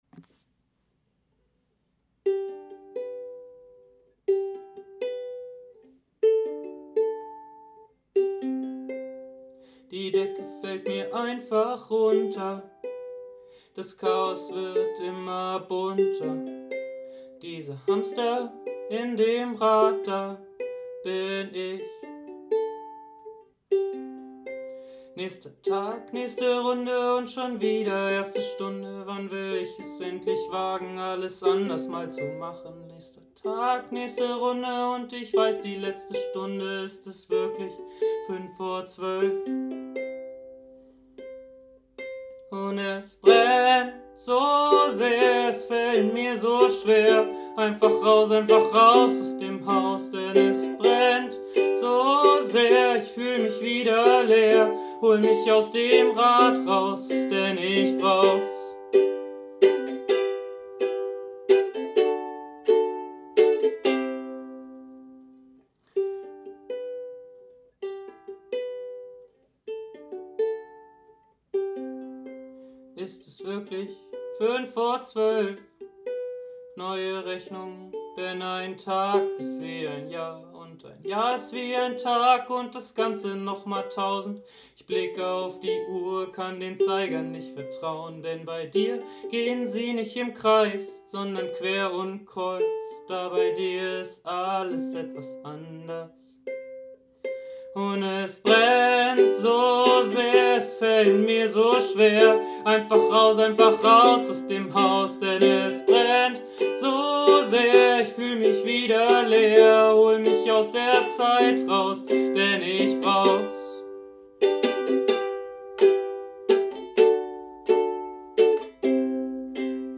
mit einer Mischung aus Gesang und Rap
Dabei begleitet er sich meist selbst mit der Ukulele, manchmal trommelt spontan ein Freund, geplant sind Akkordeonbegleitung und elektronische Beats.